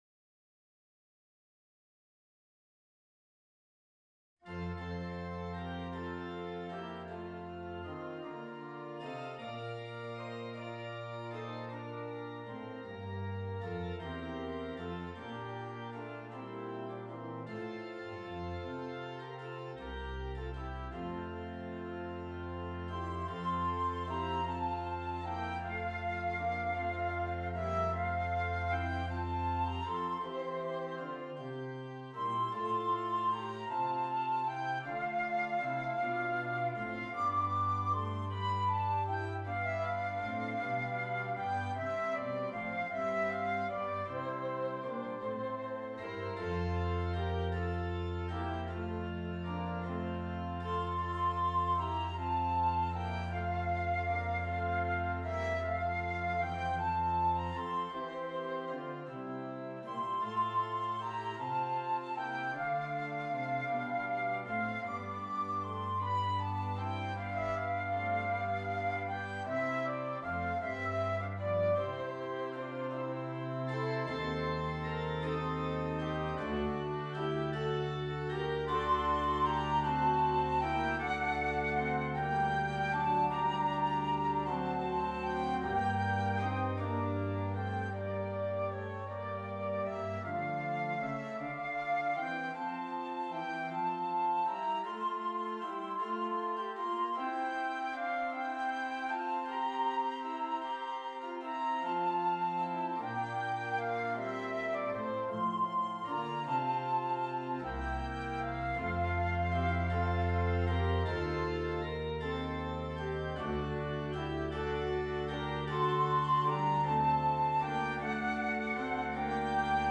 Flute and Keyboard